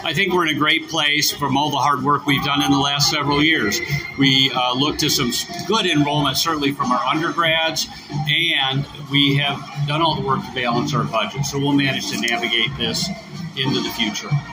IUP kicked off the academic year with a program this morning at Fisher Auditorium.